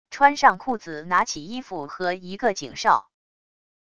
穿上裤子拿起衣服和一个警哨wav音频